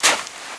HauntedBloodlines/STEPS Sand, Walk 23.wav at main
STEPS Sand, Walk 23.wav